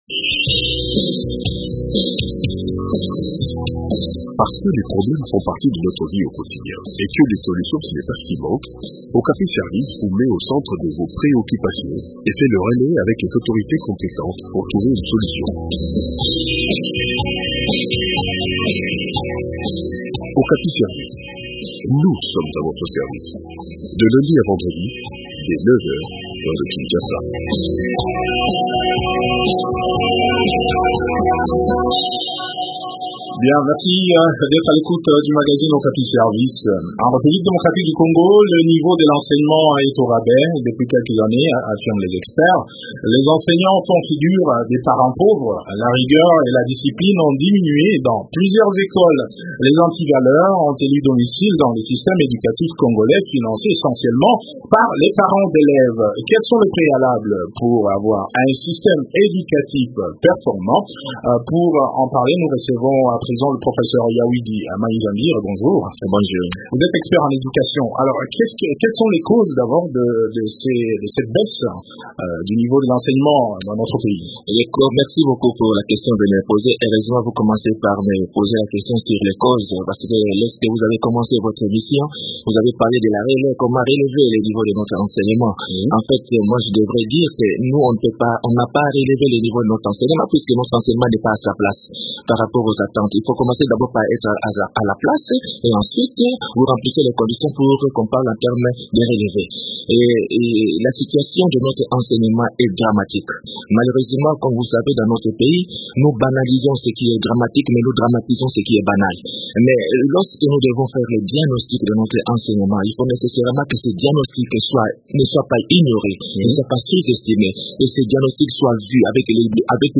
Les détails dans cet entretien